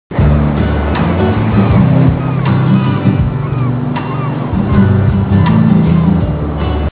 Comment: rock/country